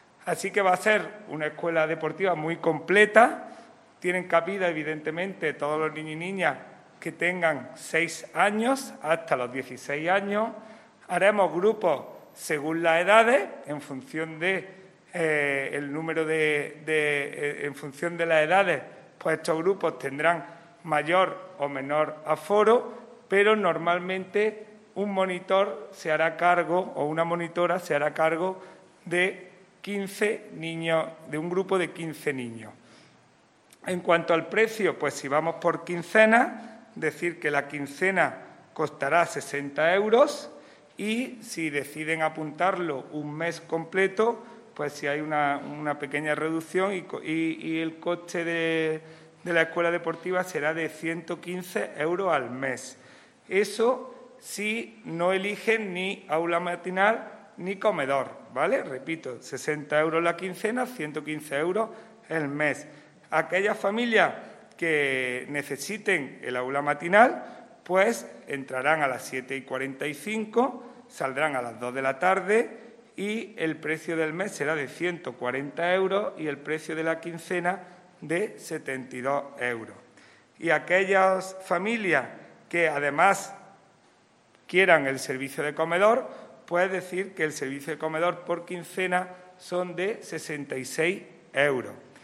El teniente de alcalde delegado de Deportes, Juan Rosas, ha presentado hoy en rueda de prensa la Escuela Deportiva de Verano 2021, iniciativa que promueve el Área de Deportes del Ayuntamiento de Antequera con el objetivo de facilitar la conciliación familiar y laboral durante el mes de julio, época ya de vacaciones en los colegios.
Cortes de voz